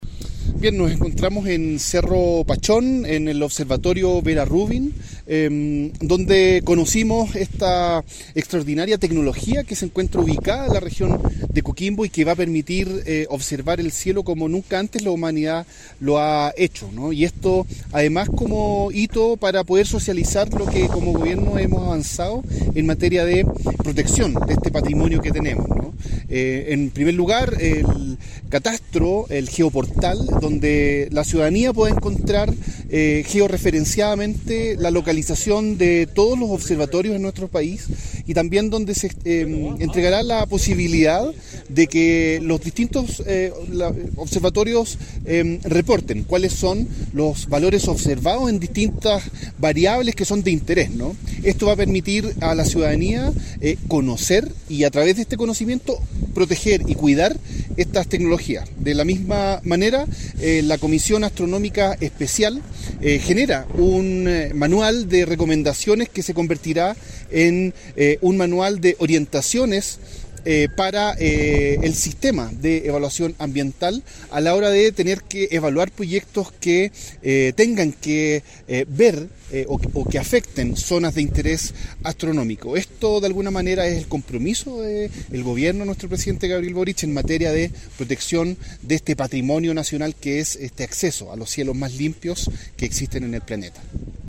Cristian-Cuevas-SUBSECRETARIO-DE-CIENCIAS.mp3